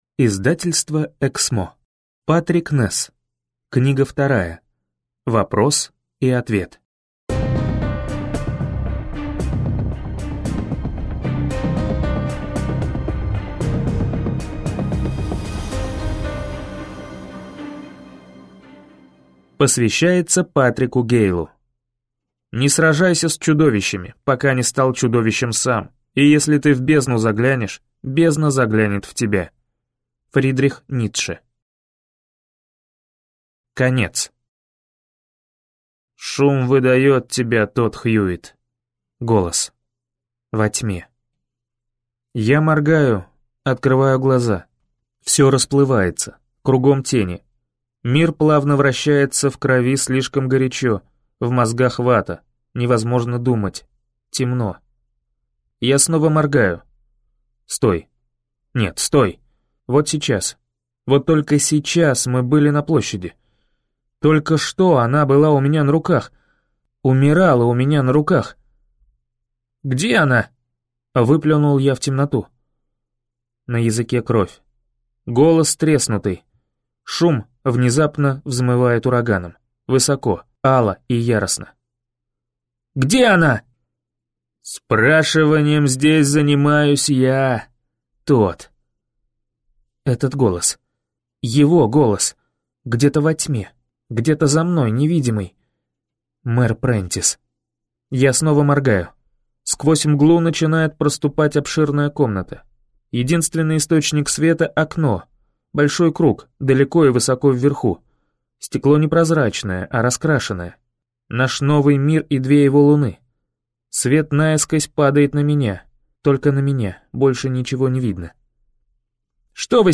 Аудиокнига Вопрос и ответ - купить, скачать и слушать онлайн | КнигоПоиск